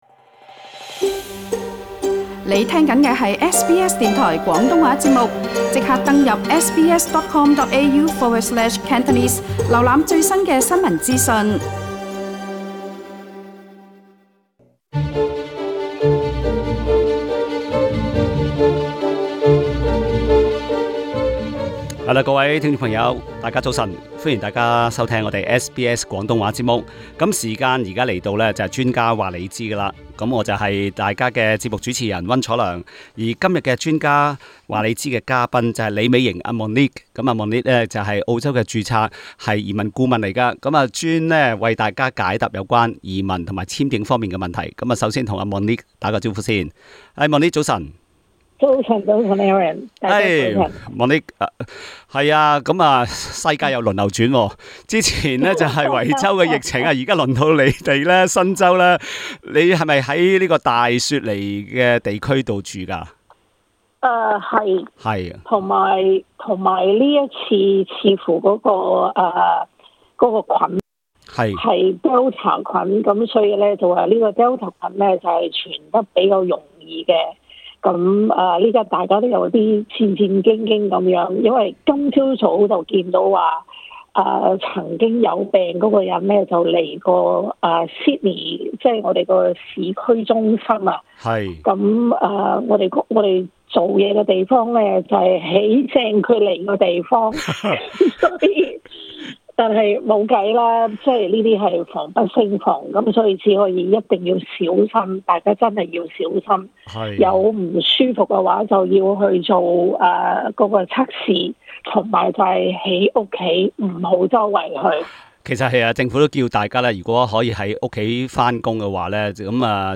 在本集【專家話你知】Talkback 環節